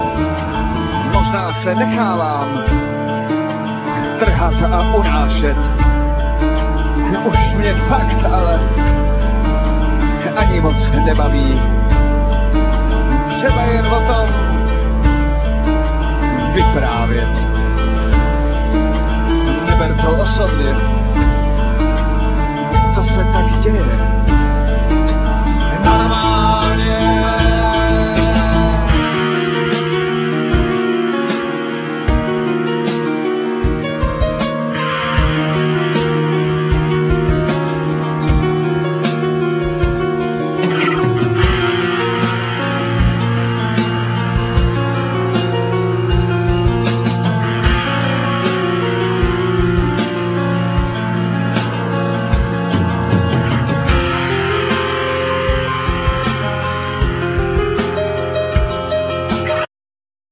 Vocal,Piano
Drums
Bass